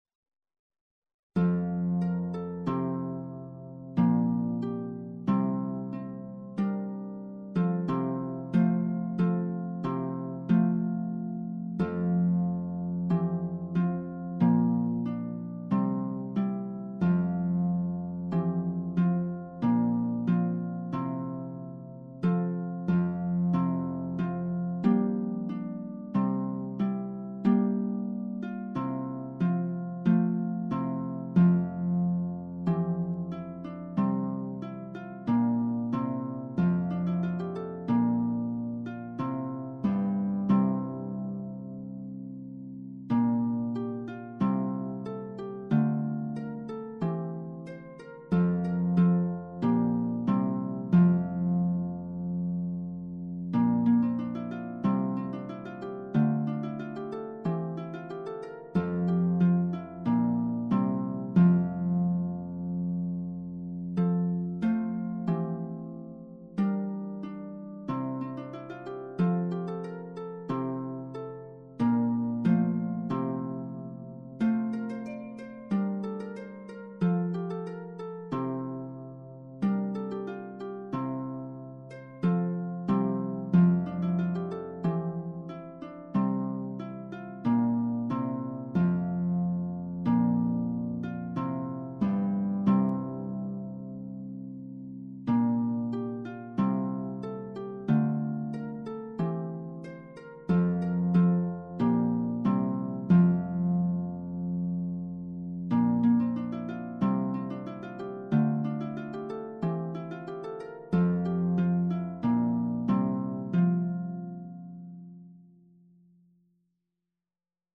Inspirational and Sacred
For Lute in G and Voice